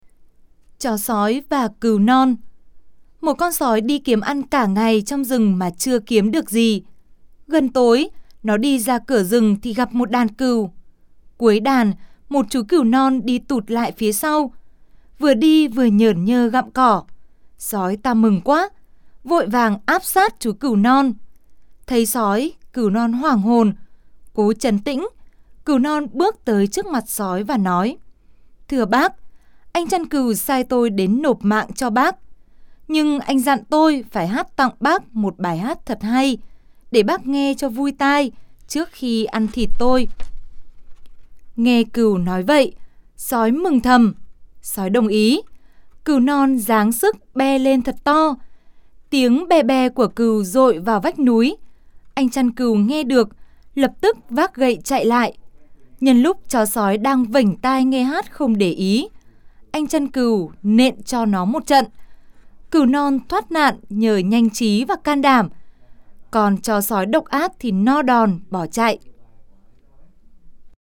Sách nói | Chó sói và cừu non